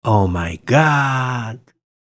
Omg-sound-effect.mp3